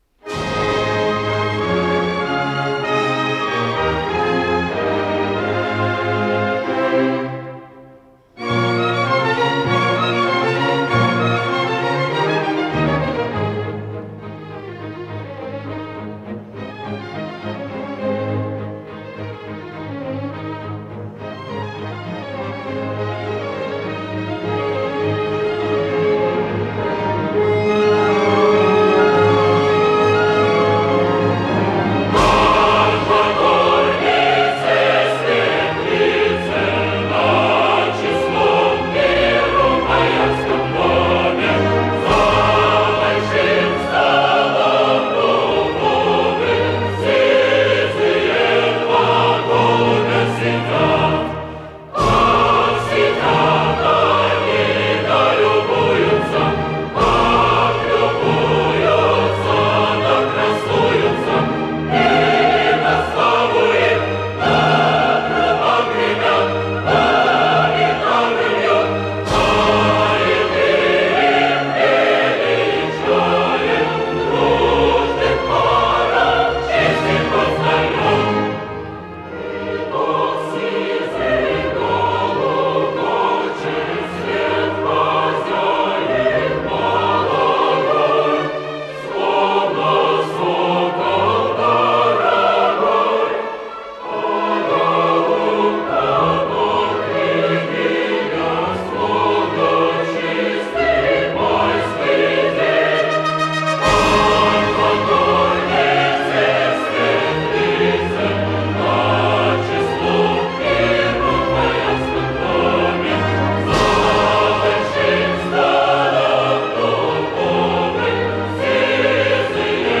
Название передачи Русалка Подзаголовок Опера в 4-х действиях и 10 картинах.